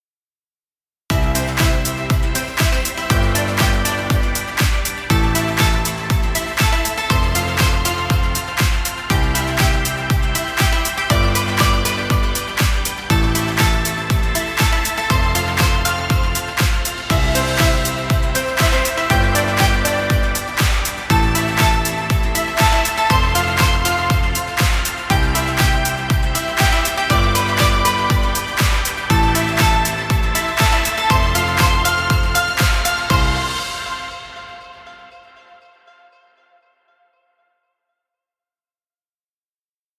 Happy fun music.